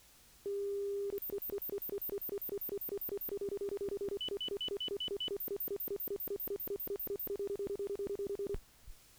動作音
【Auto-BLE信号音】